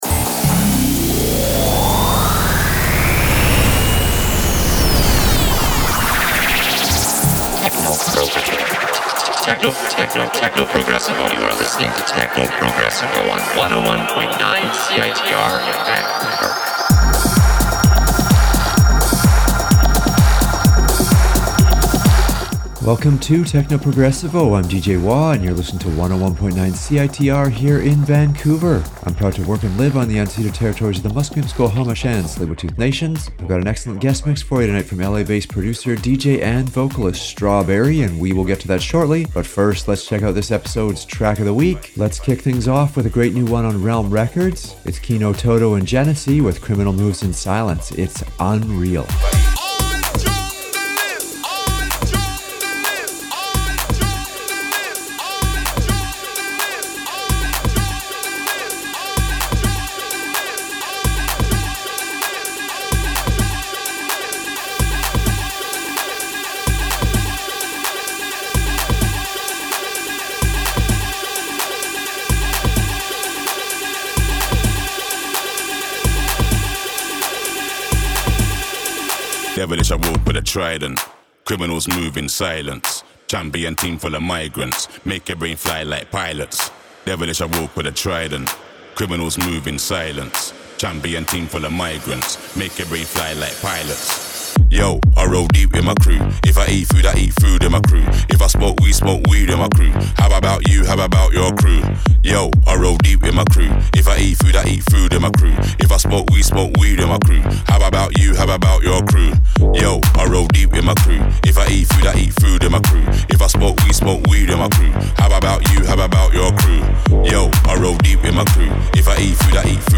Guest Mix